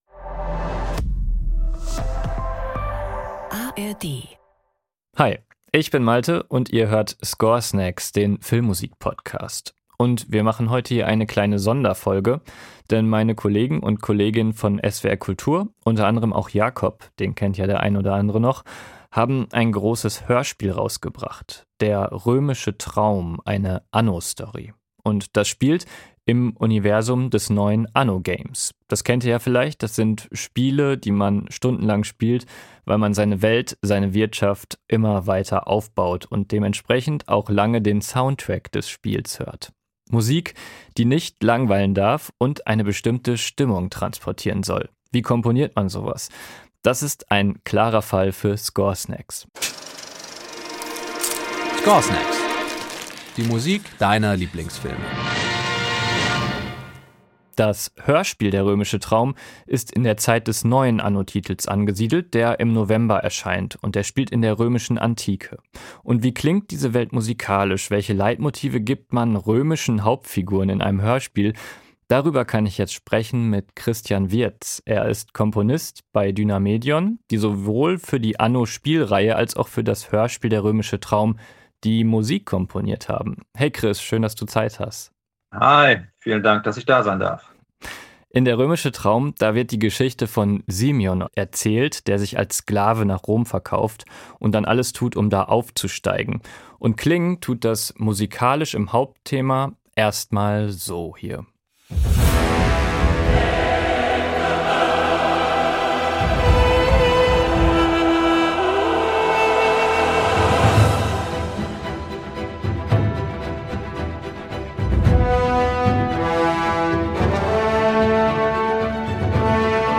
1 Sonderfolge: Die Musik des Anno-Universums 15:04 Play Pause 3d ago 15:04 Play Pause Lejátszás később Lejátszás később Listák Tetszik Kedvelt 15:04 Heute gibt es ein Interview in Score Snacks!